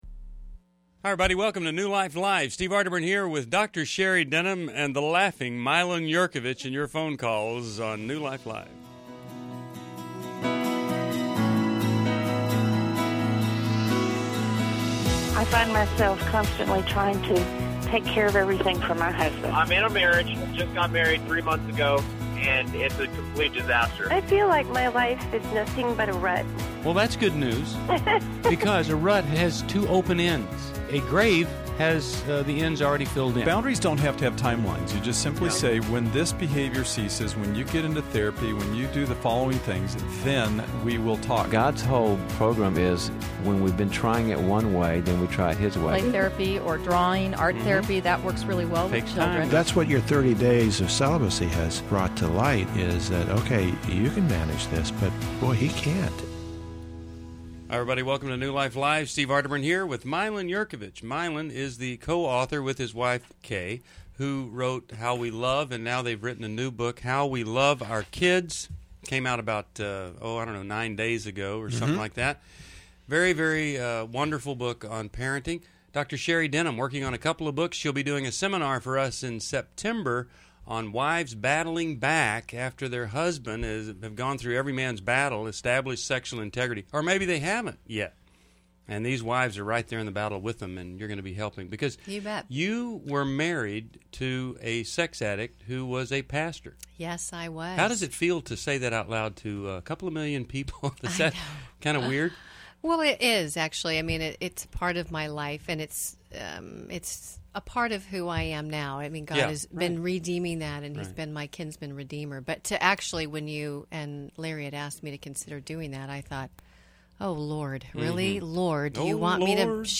Explore marriage, dating, and self-worth in New Life Live: March 24, 2011. Join hosts for insightful advice on relationships and healing.
Caller Questions: 1.